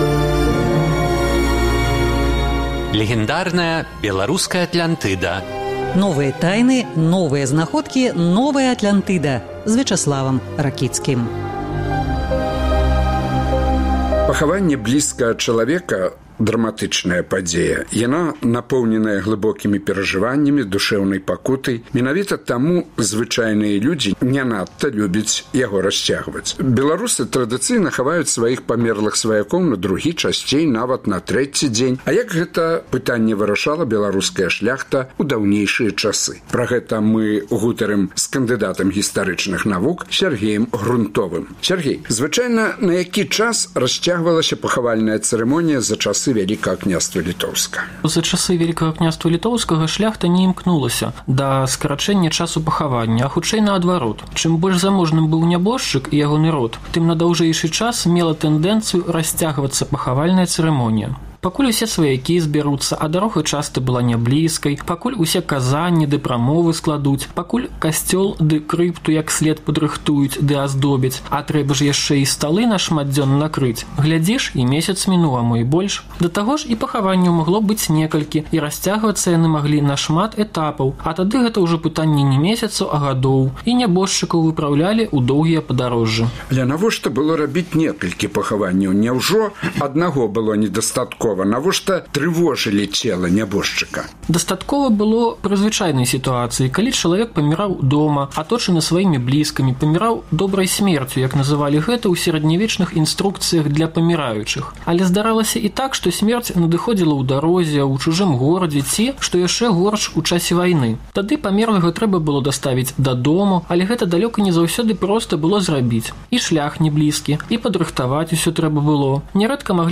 Беларусы традыцыйна хаваюць сваіх памерлых сваякоў на другі, часьцей нават на трэці дзень. А як гэта пытаньне вырашала беларуская шляхта ў старадаўнія часы? Гутарка